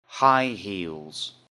high-heels.mp3